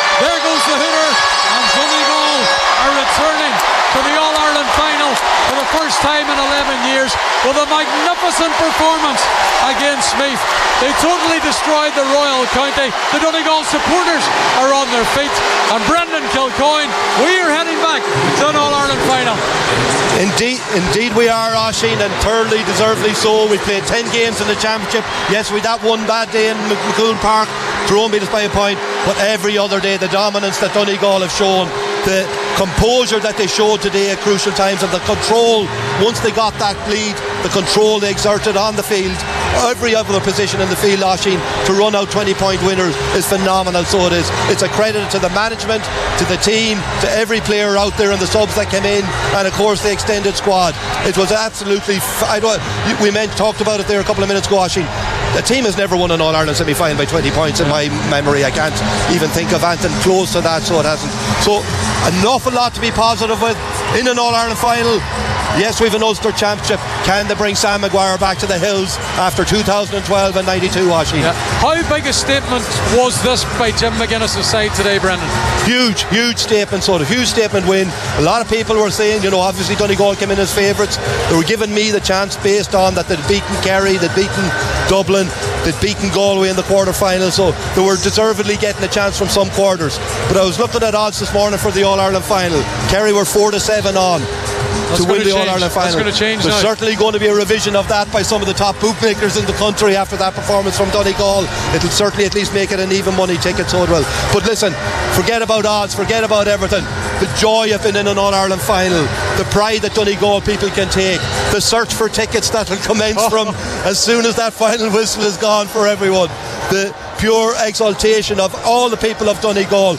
Donegal blow Meath away to reach first All-Ireland Final since 2014 - Post-Match Reaction
were live at full time for Highland Radio Sunday Sport…